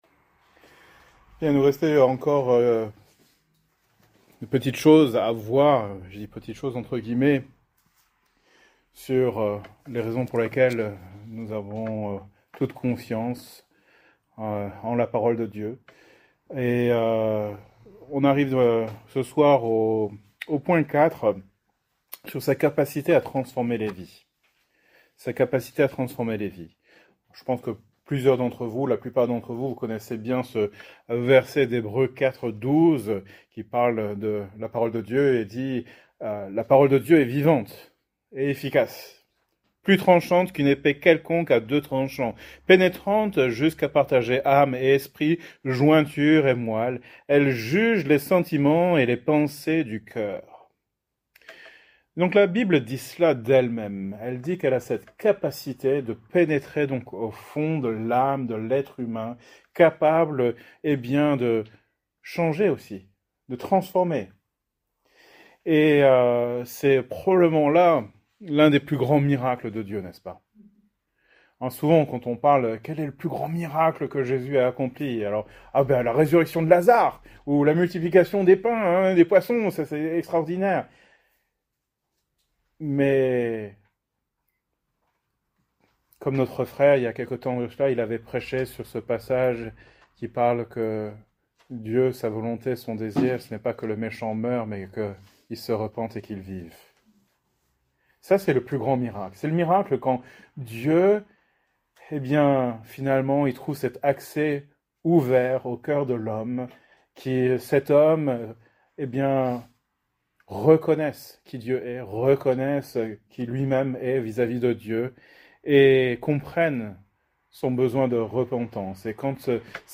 Thème: Apologétique , Foi Genre: Etude Biblique